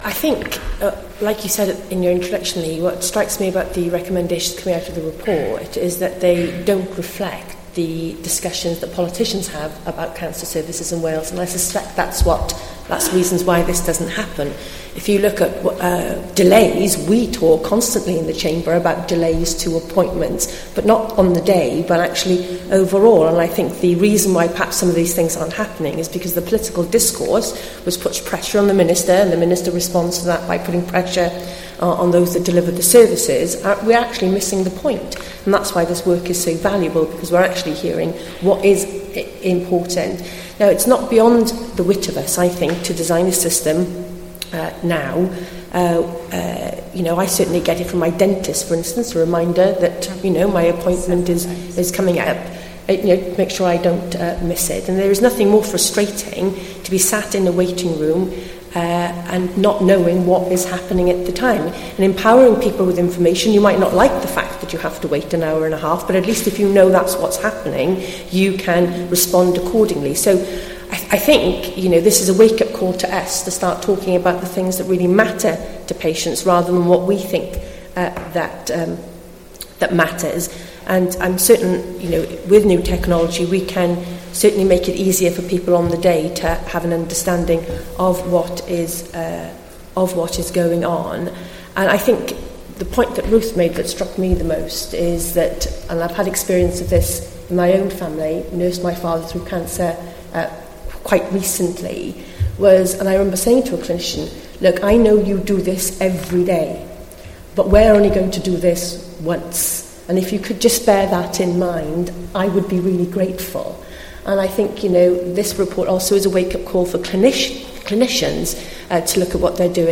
Leader of the Welsh Liberal Democracts, Kirsty Williams AM, gives her response to the IWA's Let's talk cancer report.